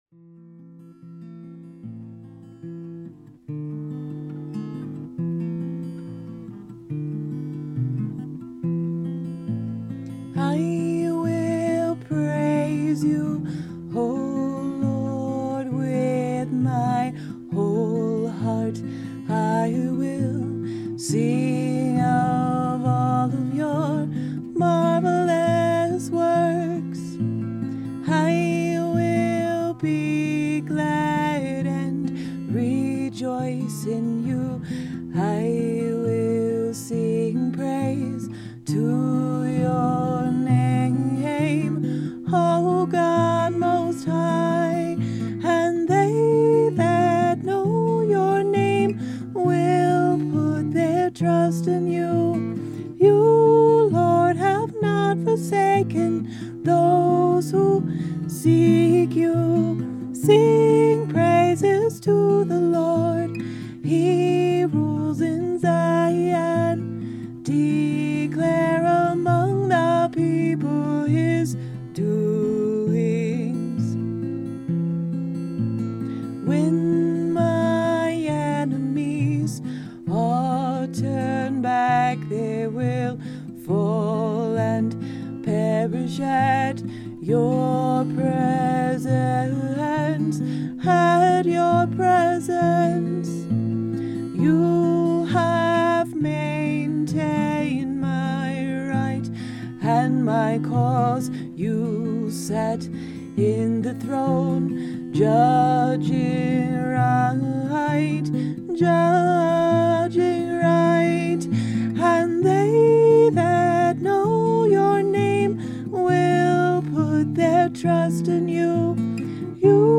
AM, DM, E7, E